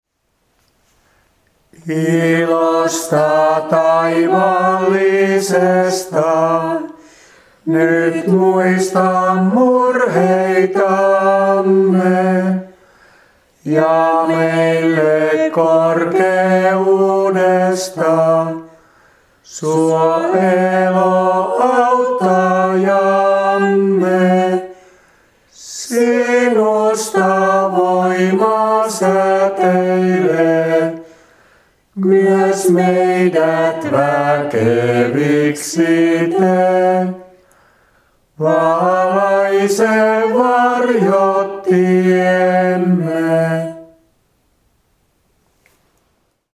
Siionin virren 54 säkeistö 4: